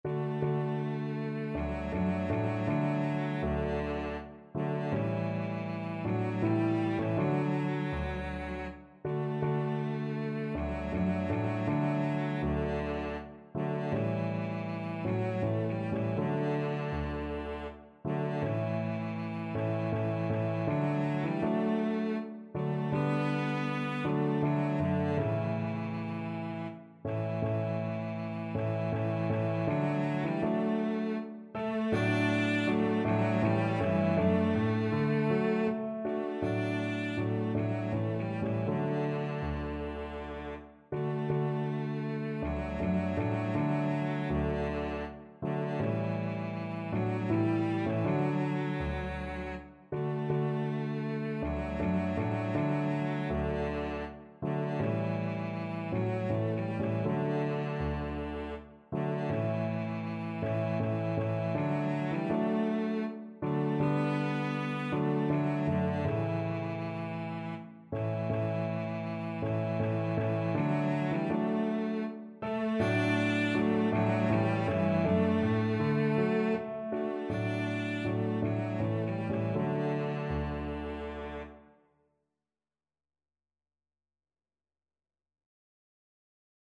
Cello
3/4 (View more 3/4 Music)
D major (Sounding Pitch) (View more D major Music for Cello )
Andante
Classical (View more Classical Cello Music)